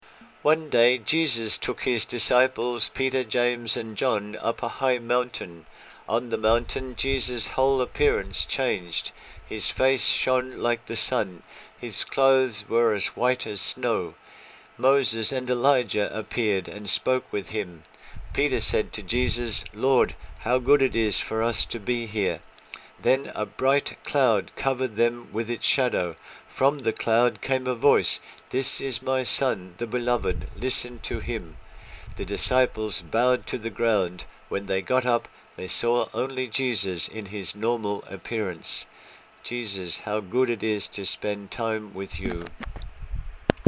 The Book Blog:   4 readings + recordings
My recording of this reading